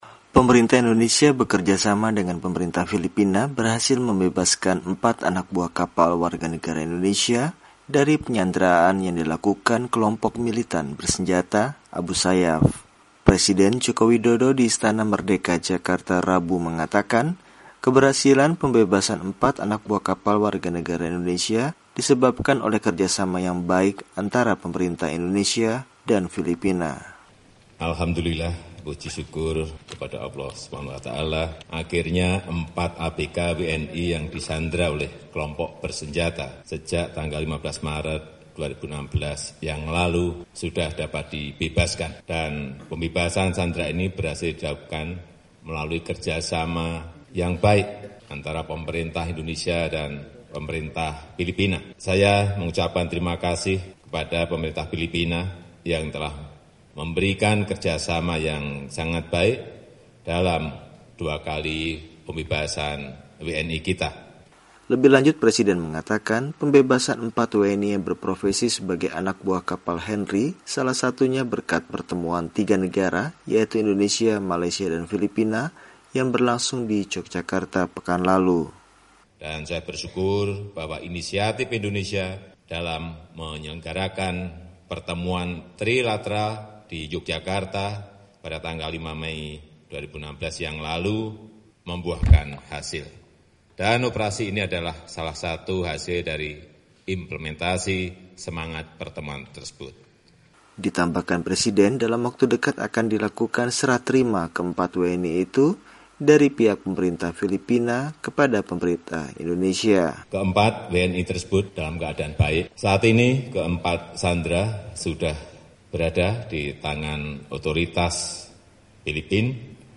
Pernyataan Presiden Jokowi Tentang Pembebasan 4 Sandera WNI